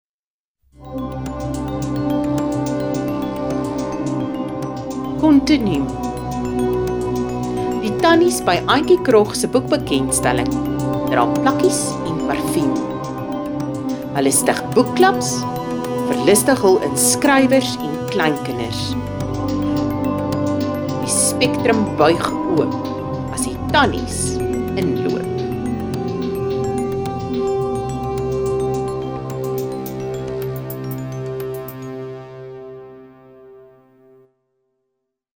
A collection of textured beats featuring artists’ contributions to Ons Klyntji 2023.